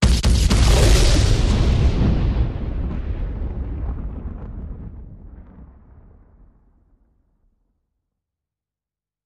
Explosions; Multiple 03